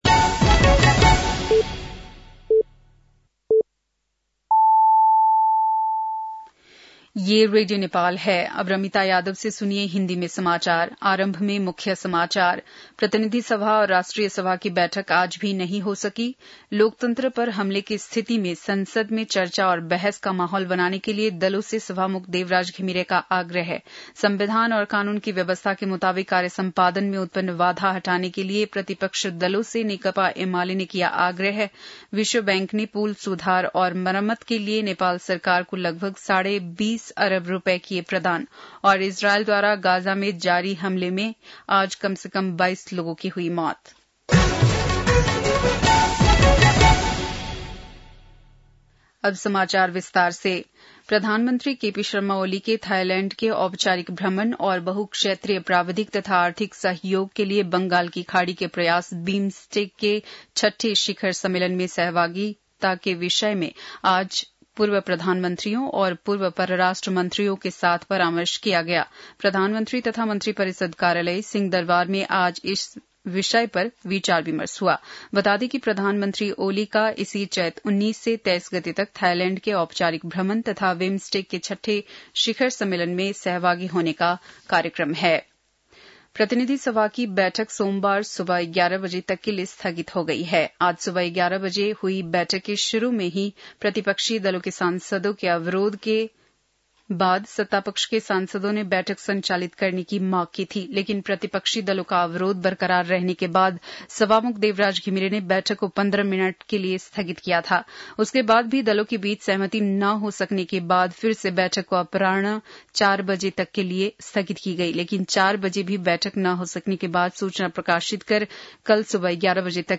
बेलुकी १० बजेको हिन्दी समाचार : १७ चैत , २०८१